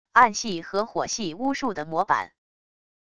暗系和火系巫术的模版wav音频